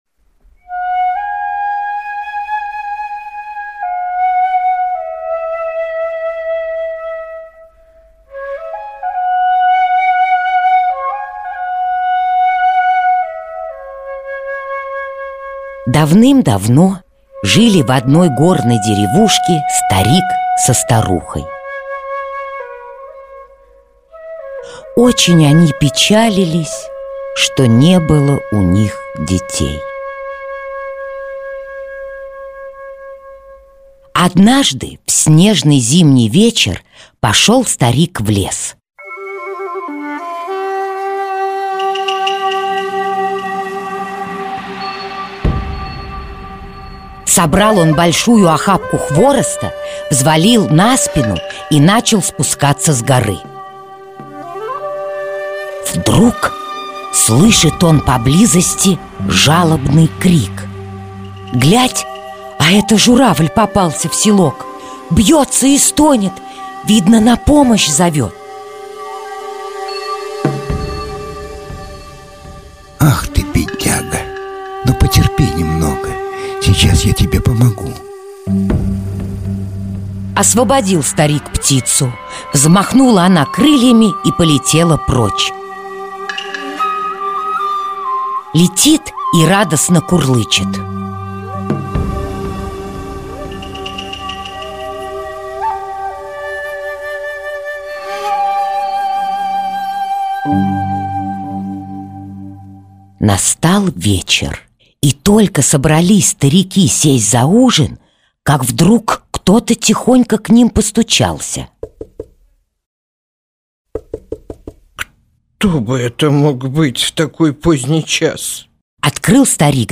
Журавлиные перья - японская аудиосказка - слушать онлайн